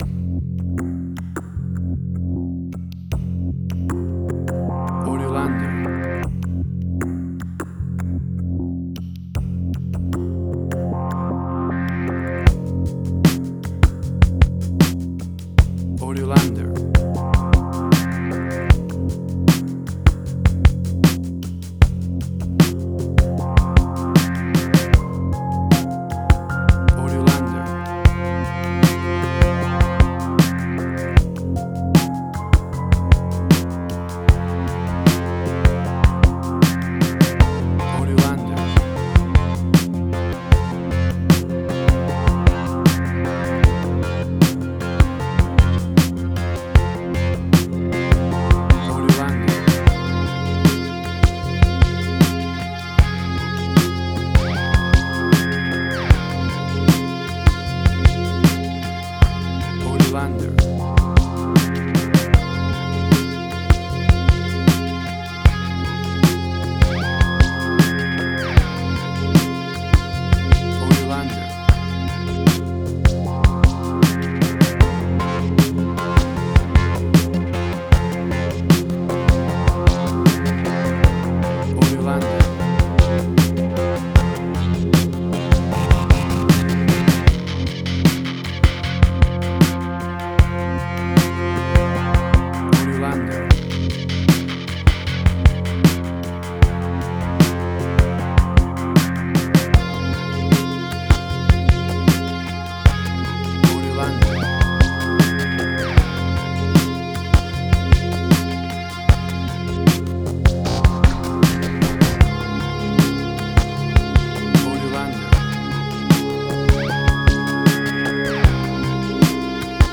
Suspense, Drama, Quirky, Emotional.
WAV Sample Rate: 16-Bit stereo, 44.1 kHz
Tempo (BPM): 78